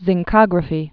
(zĭng-kŏgrə-fē)